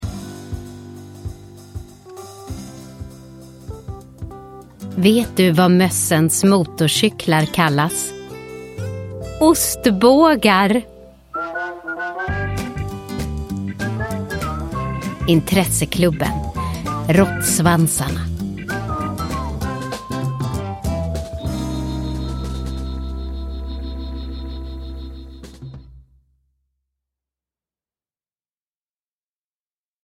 Råttsvansarna – Ljudbok – Laddas ner